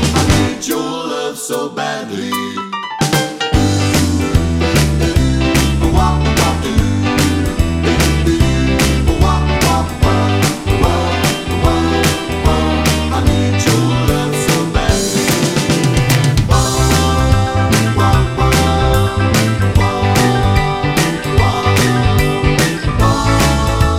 No Saxophone Rock 'n' Roll 2:33 Buy £1.50